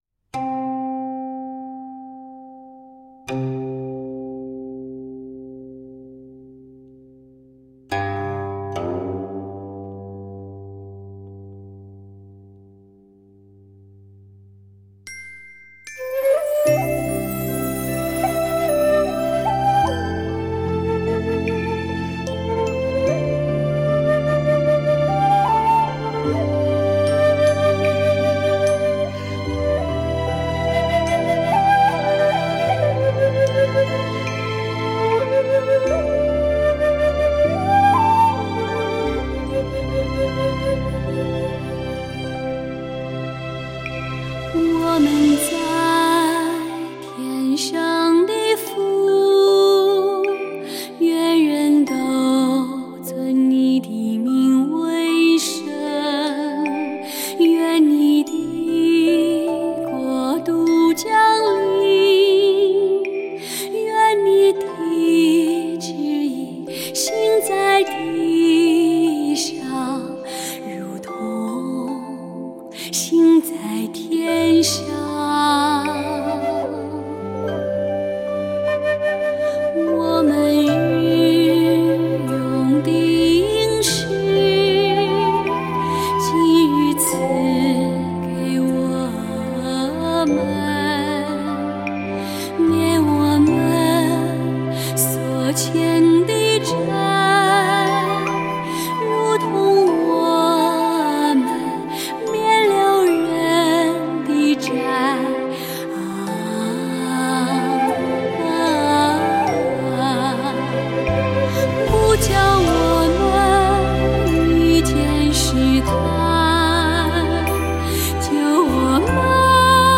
颂赞：《主祷文》